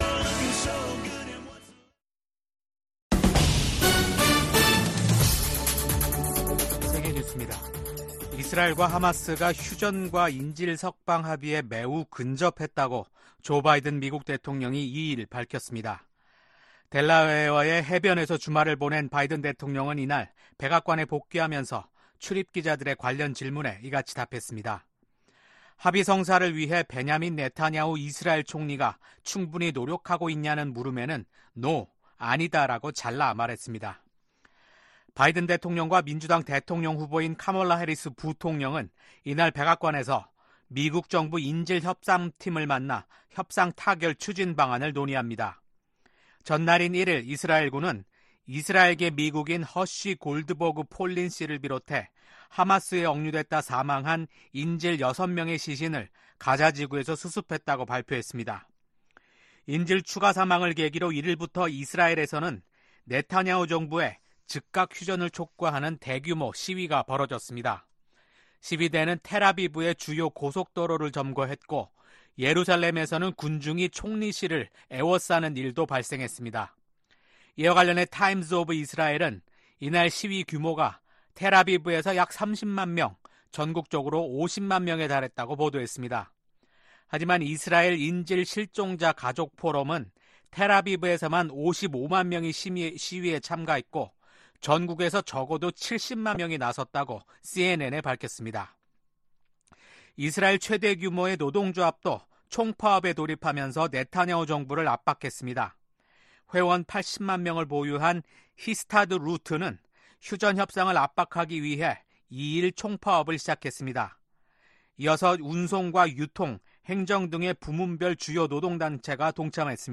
VOA 한국어 아침 뉴스 프로그램 '워싱턴 뉴스 광장' 2024년 9월 3일 방송입니다. 대북 억지력 운용 방안 등을 논의하는 미한 고위급 확장억제전략협의체 회의가 미국 워싱턴에서 열립니다. 미국 정부의 대북정책 목표는 여전히 한반도의 완전한 비핵화라고 국무부가 확인했습니다.